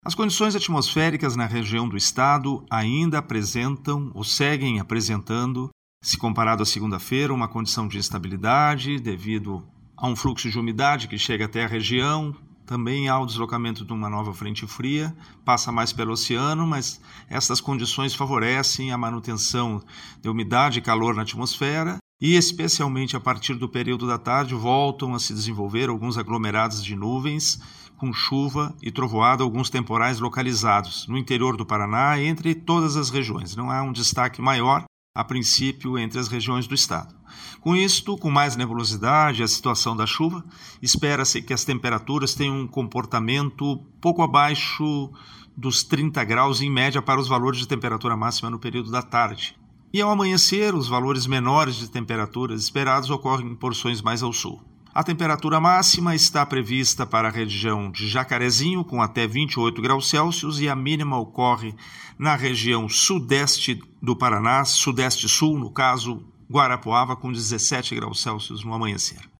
Ouça a previsão detalhada com o meteorologista do Simepar